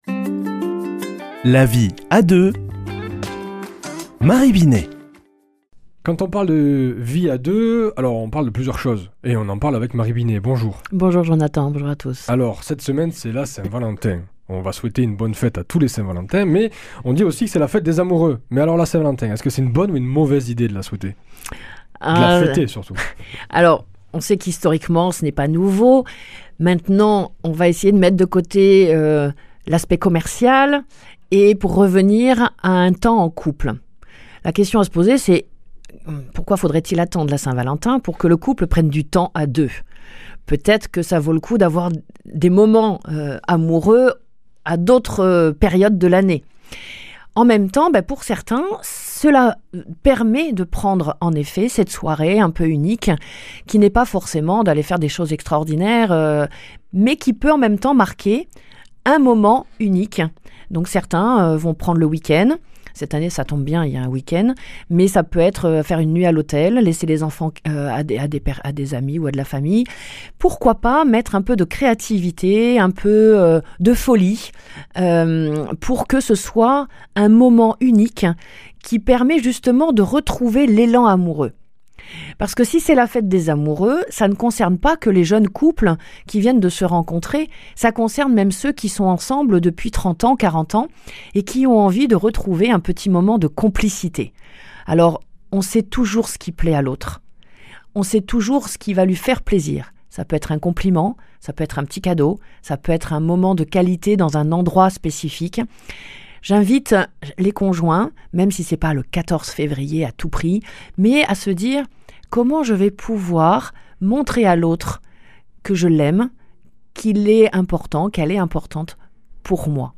mardi 11 février 2025 Chronique La vie à deux Durée 4 min